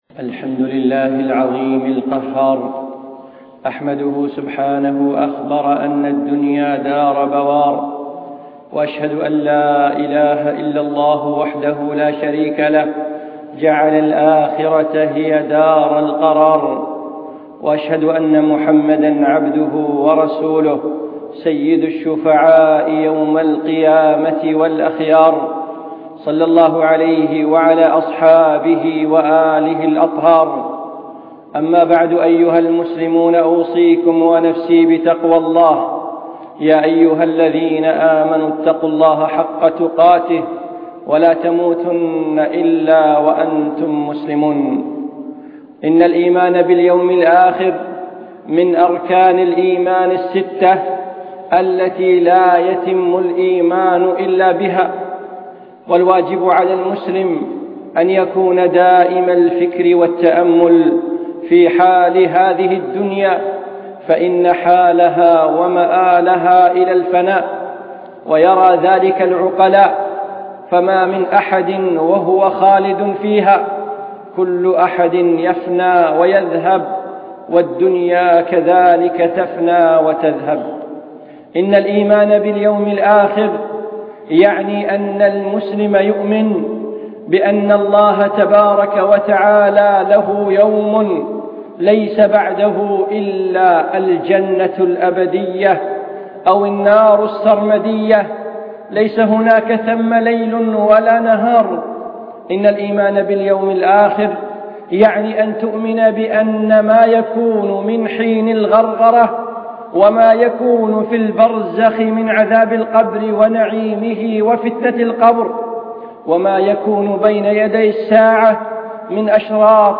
من خطب الشيخ في دولة الإمارات
الإيمان باليوم الآخر - خطبة مؤثرة